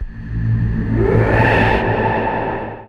VEC3 FX Athmosphere 04.wav